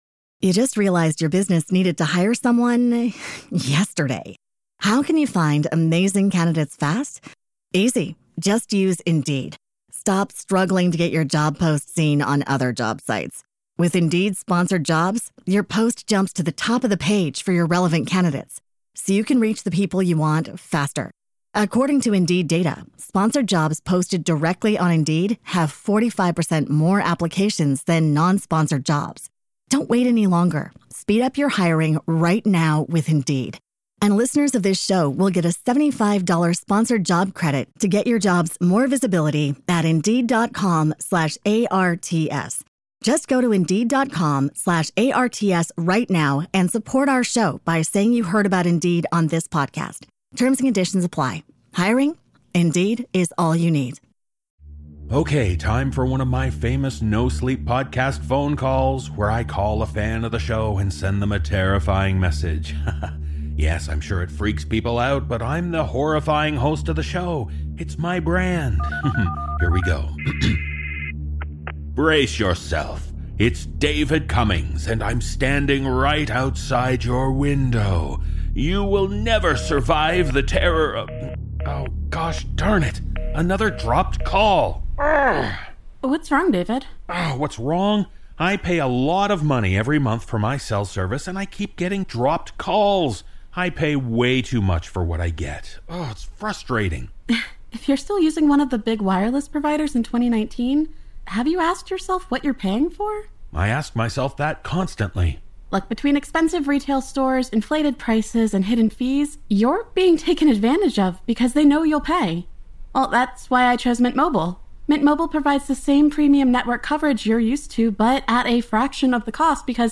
Cast: Narrator